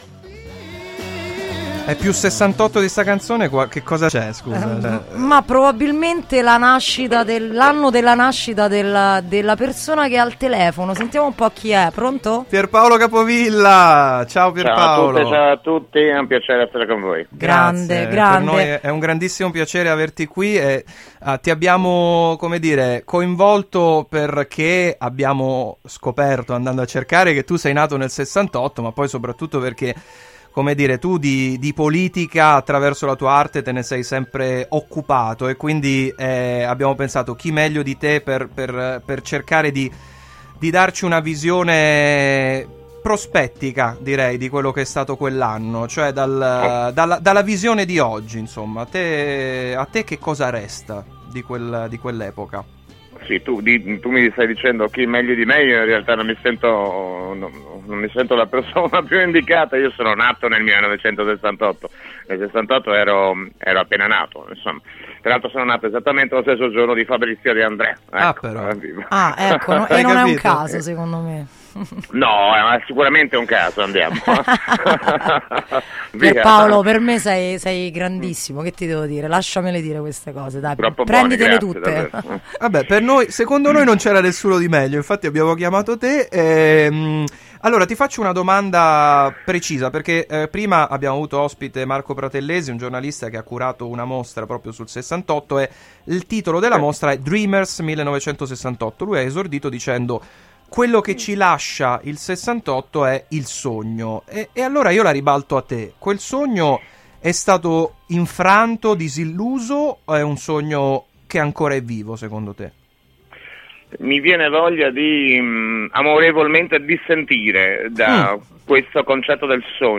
intervistano
intervista-a-Pierpaolo-Capovilla.mp3